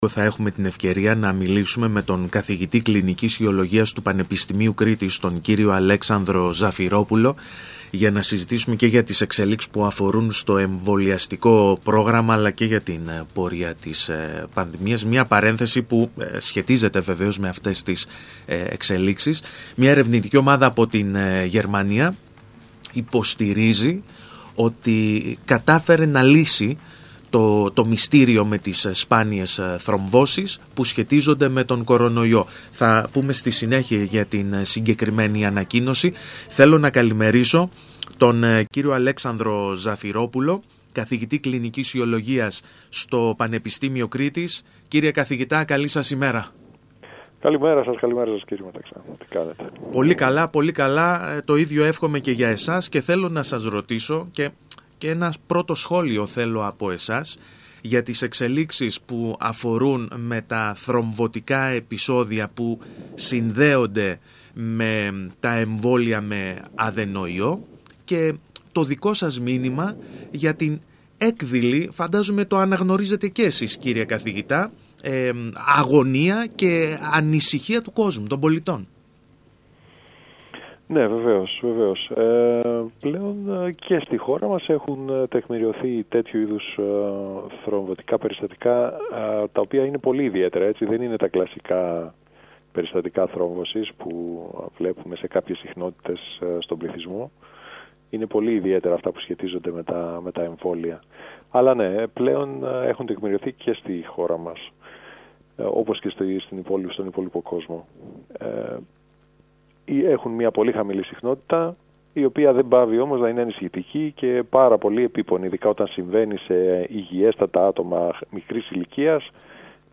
μιλώντας στον ΣΚΑΪ Κρήτης 92.1